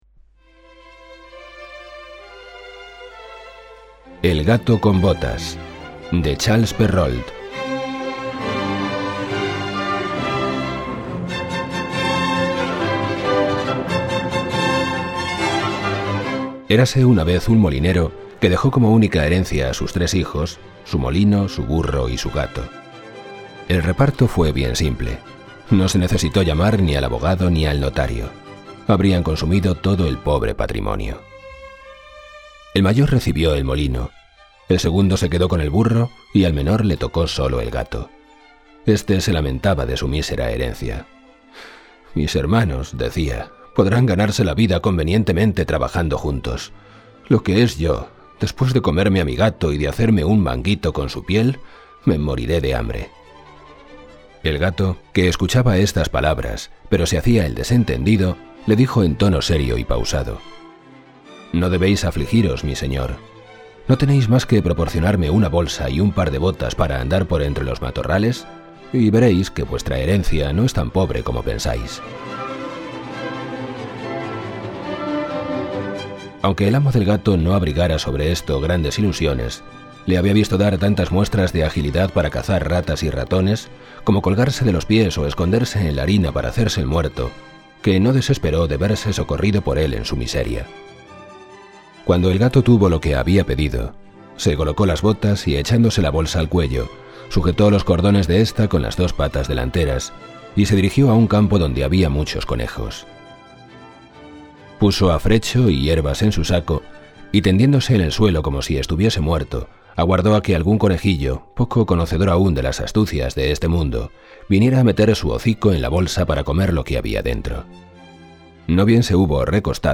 Música: MusOpen
Audiolibro: El gato con botas